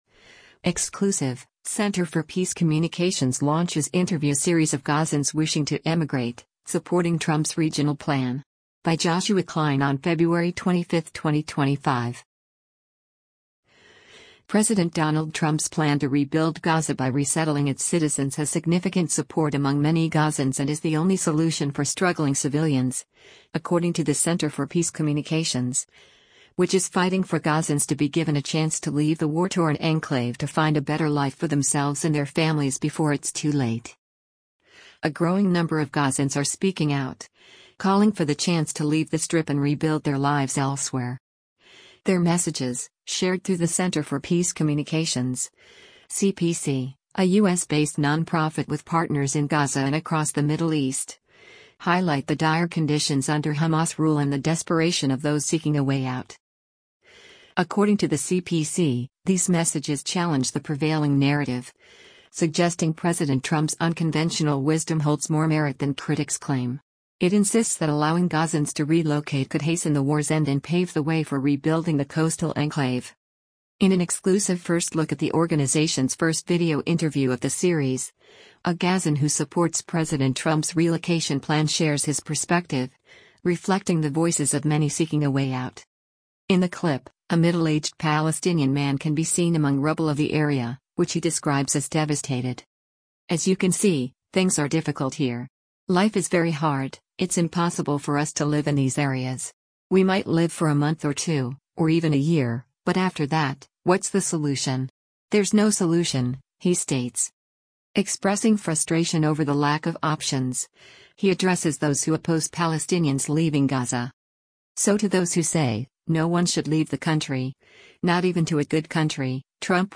In the clip, a middle-aged Palestinian man can be seen among rubble of the area, which he describes as “devastated.”